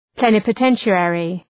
Προφορά
{,plenıpə’tenʃı,erı}
plenipotentiary.mp3